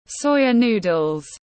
Miến tiếng anh gọi là soya noodles, phiên âm tiếng anh đọc là /ˈsɔɪ.ə nuː.dəl/
Soya noodles /ˈsɔɪ.ə nuː.dəl/